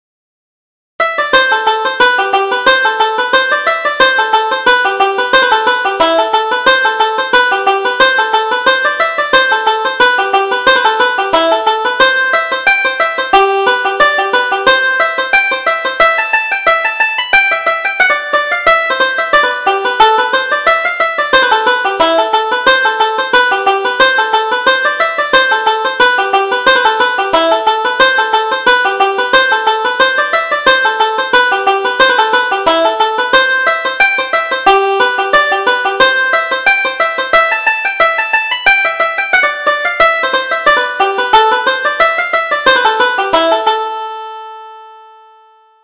Reel: The Watchmaker